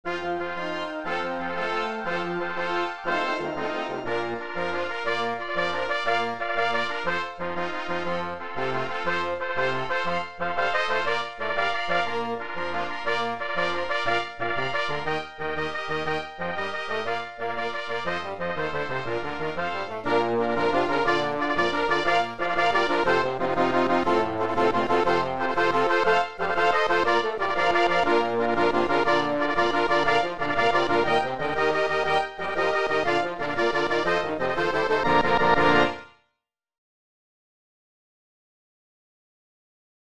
I dropped it to the key of Bb from Eb and plugged it in.
MARCH MUSIC